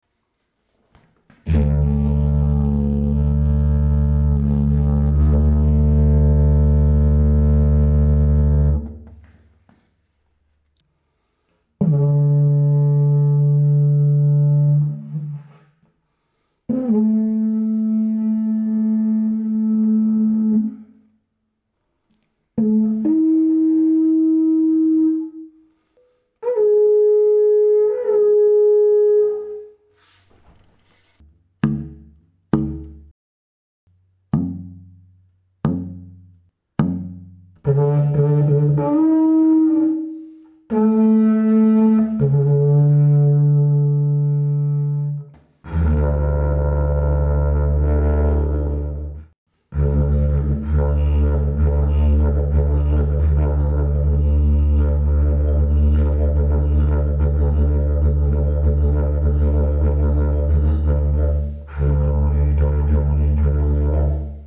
The Dg555 is a medium-sized didgeridoo with a pitch of D2.
It responds very easily, requires little air, and has a strong, very deep bass fundamental tone. 3.0 kg
D2-10 (Db to D+20) // D3+-20 / A3-20 / D4#+40 / G4#+20
is a technical sample to show the overtones, the plop resonance and the range to pull the basic key